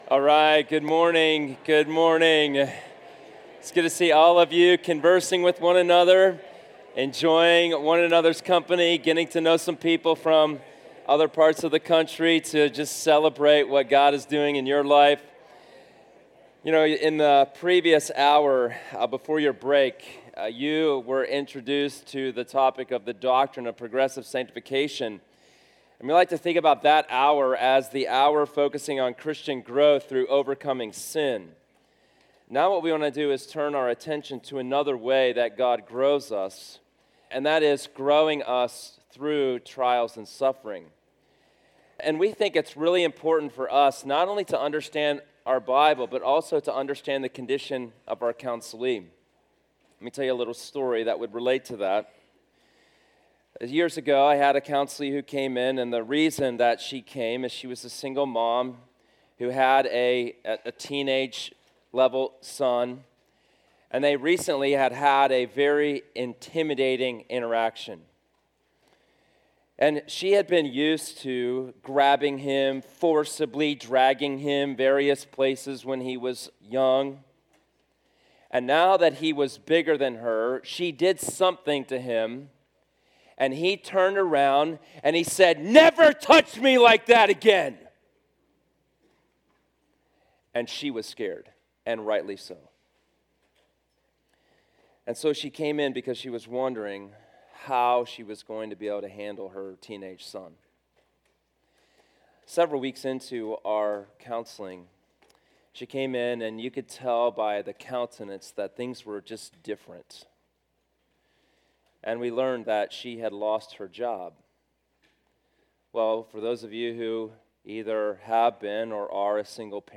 This is a session from the Biblical Counseling Training Conference hosted by Faith Church in Lafayette, Indiana.
You may listen to the first 10 minutes of this session by clicking on the “Preview Excerpt” button above.